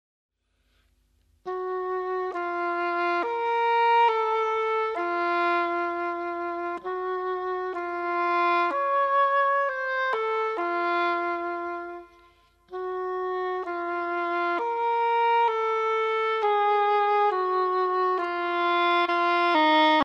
Solo na rożek angielski